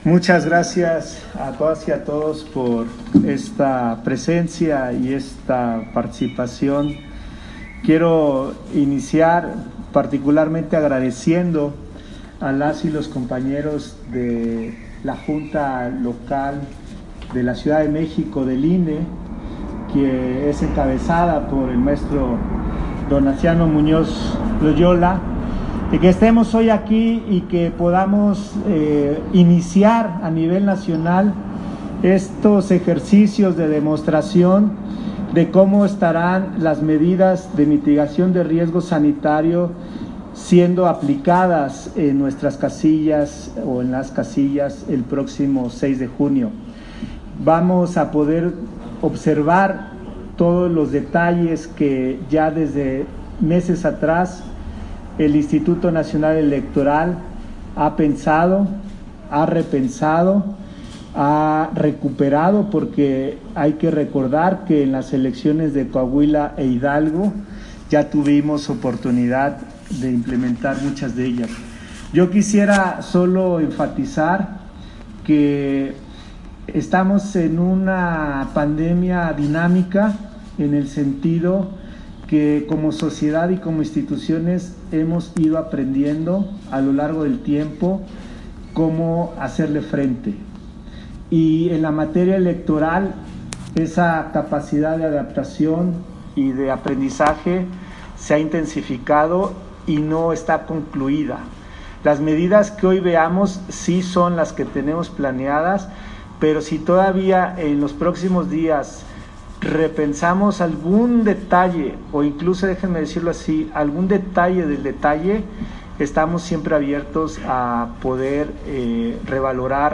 Intervención de José Roberto Ruiz, en la demostración de las medidas sanitarias que se aplicarán en las casillas el 6 de junio